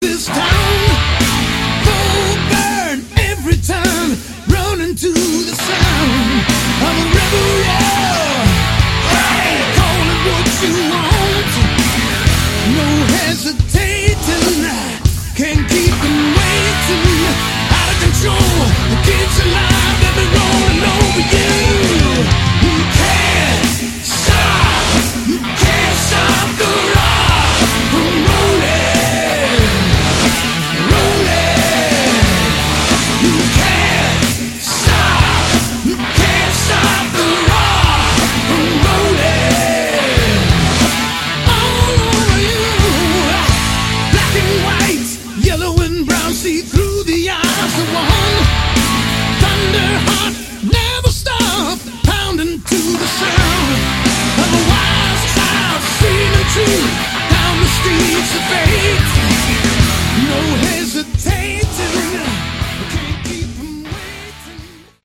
all guitars
lead vocals
bass
keyboards
drums